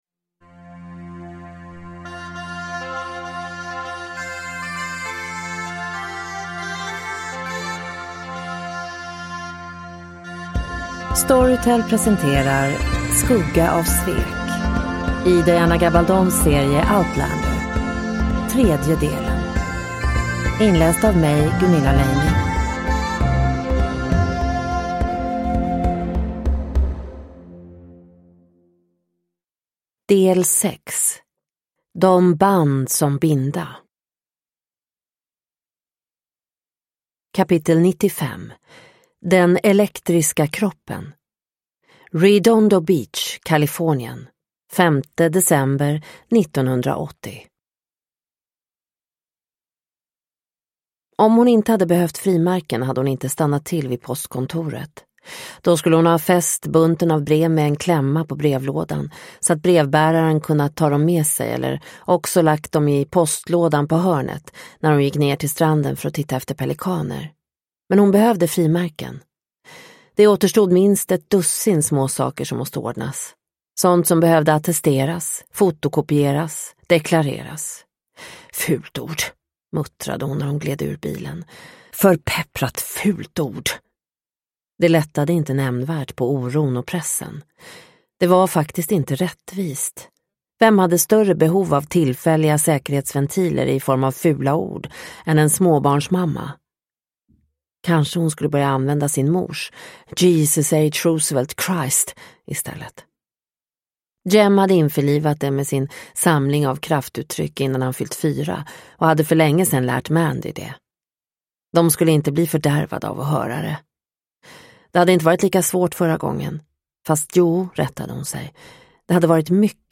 Skugga av svek - del 3 – Ljudbok – Laddas ner